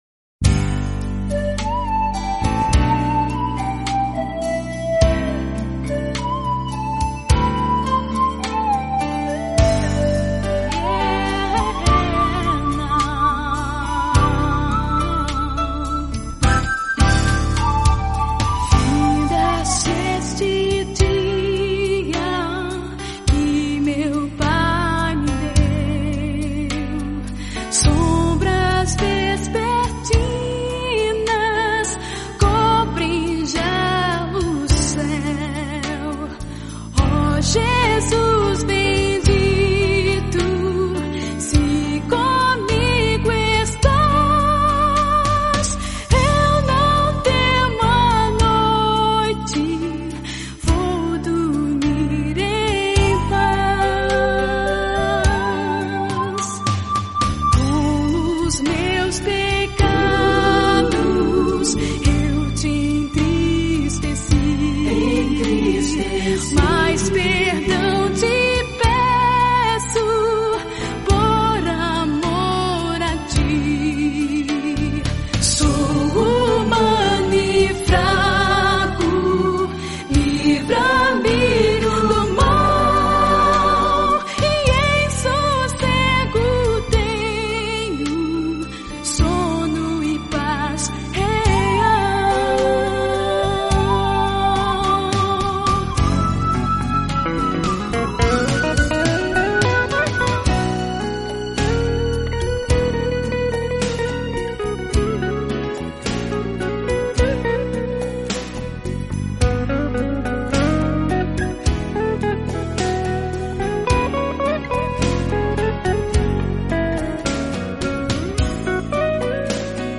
Ocorreu, no entanto, que nesta última semana estive, agora na Rádio Zé FM, para participar do programa Mensagem de Paz, das Igrejas Presbiterianas de Americana.